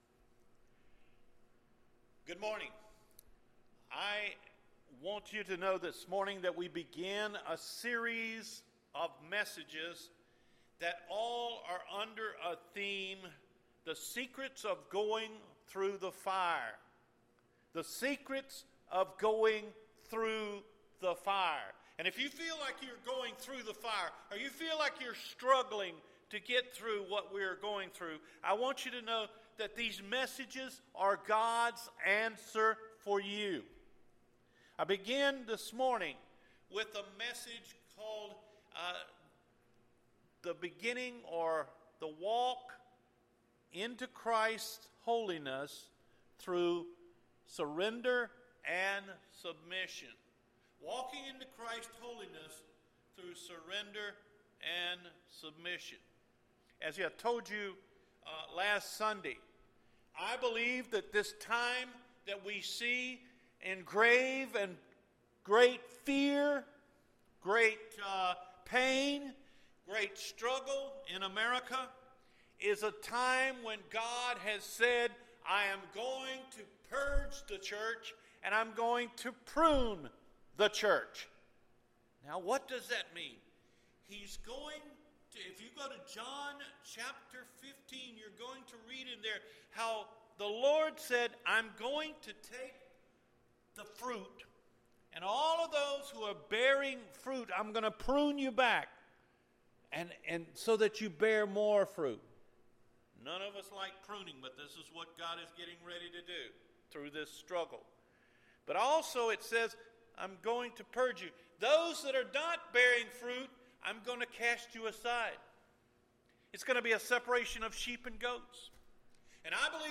Walking In His Holiness Through Surrender And Submission – Cedar Fork Baptist Church